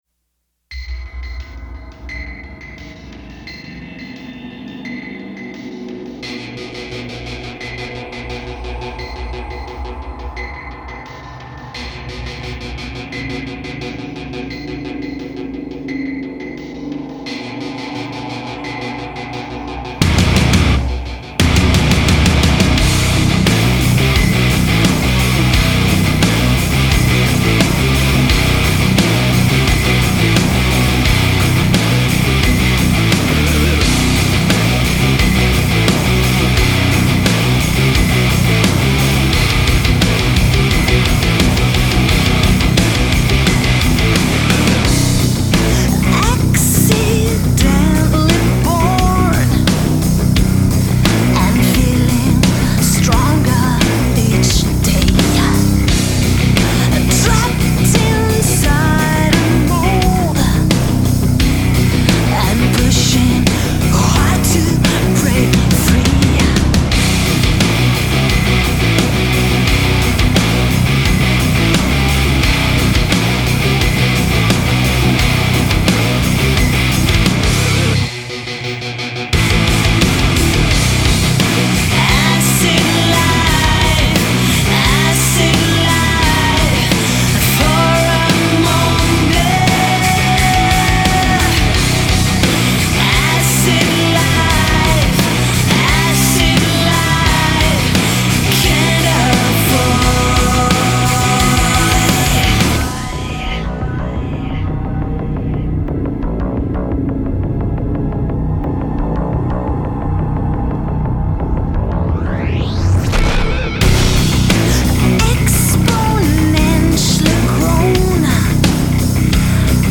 Vocals
Guitars, Keyboard, Programming
Drums
Bass